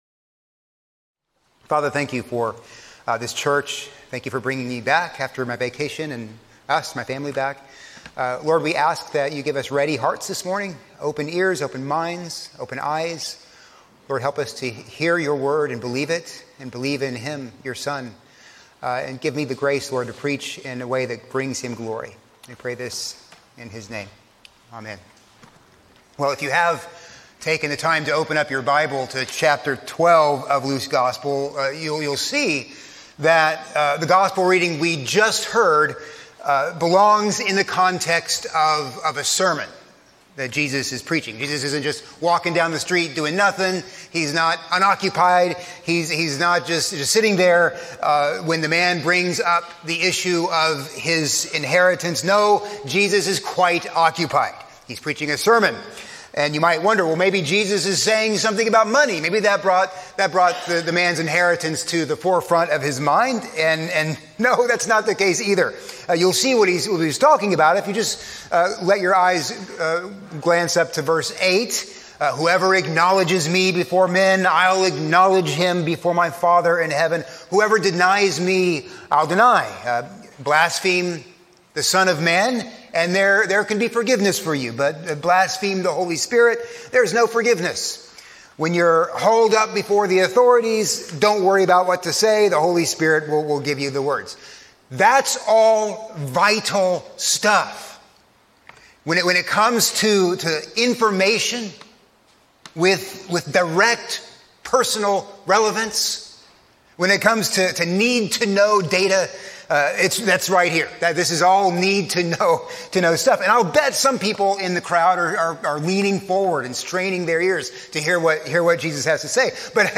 A sermon on Luke 12:13-21